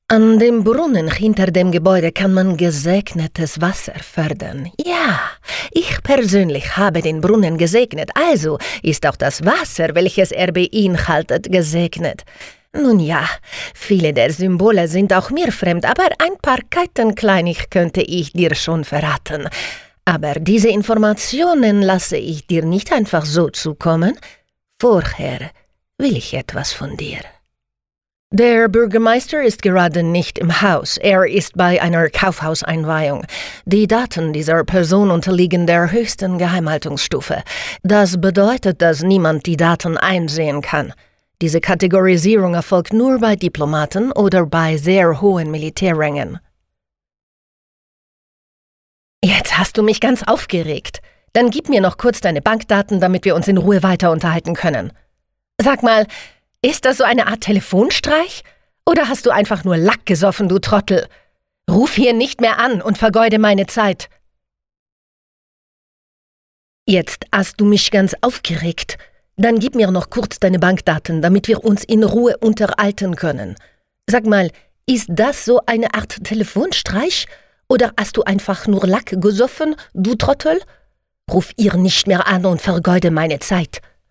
Sprecherin, Synchronsprecherin, Moderatorin, Sängerin
Akzente und Dialekte